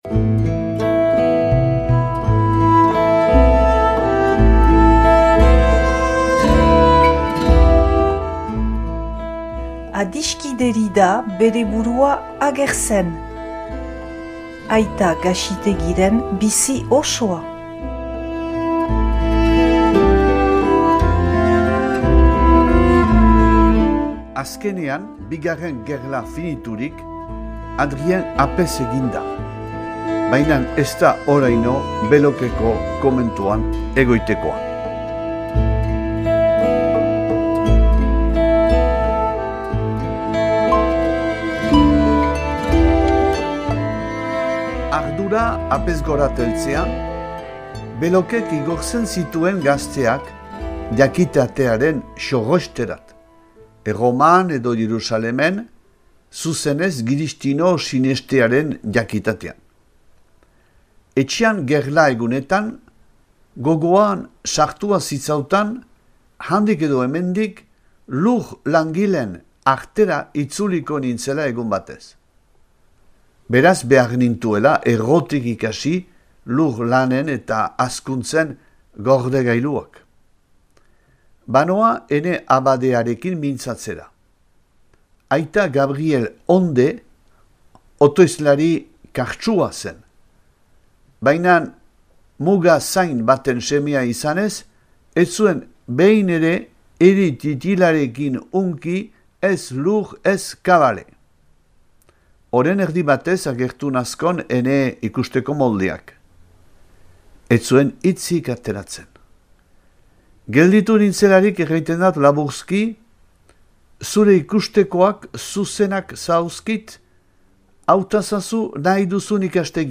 irakurketa bat dauzuegu eskaintzen.